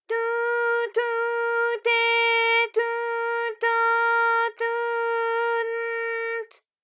ALYS-DB-001-JPN - First Japanese UTAU vocal library of ALYS.
tu_tu_te_tu_to_tu_n_t.wav